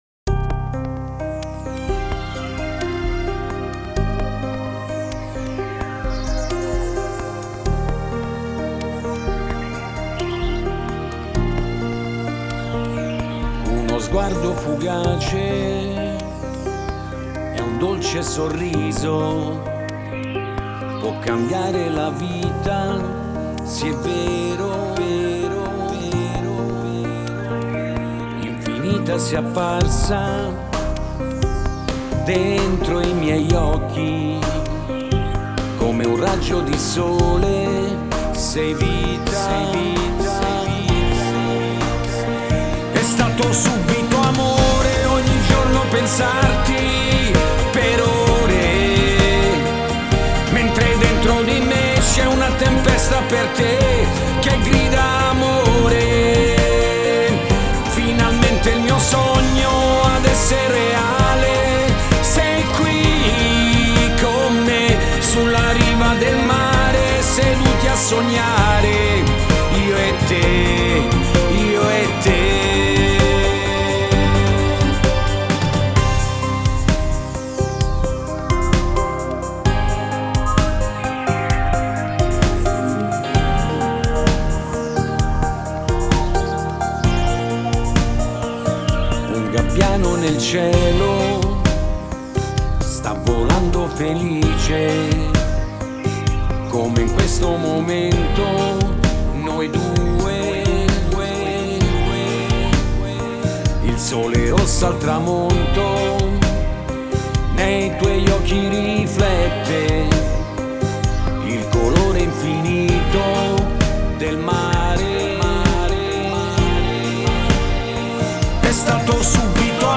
Lento